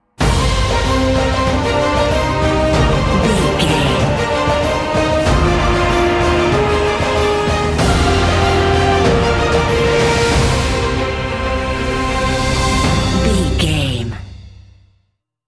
Epic / Action
Uplifting
Aeolian/Minor
driving
energetic
powerful
brass
cello
double bass
drums
horns
strings
trumpet
violin